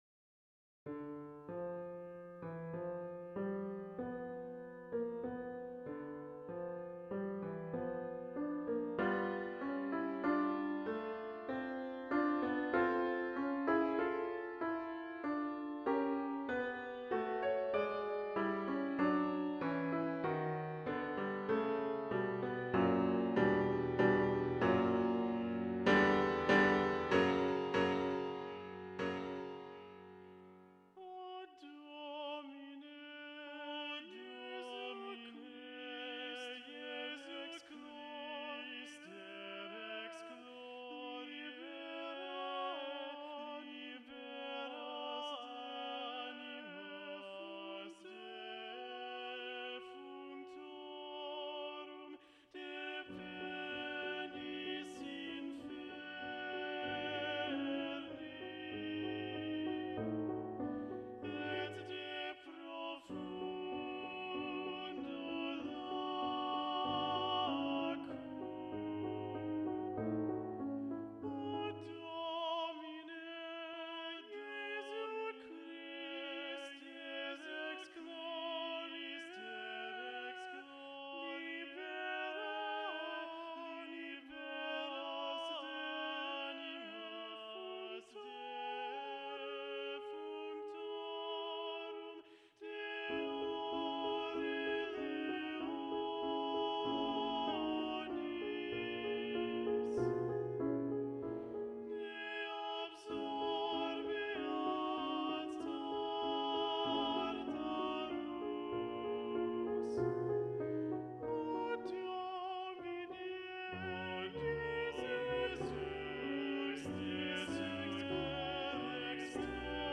Index of /Rehearsal_Tracks/Faure_Requiem/Bass 2
Offertory (Requiem) - Baritone Solo Predominant - Gabriel Faure, ed. John Rutter.mp3